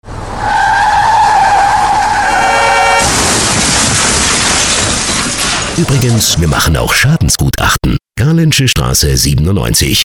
Unsere Radio Spots
Radiospot 2 |